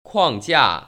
框架[ kuàngjià ]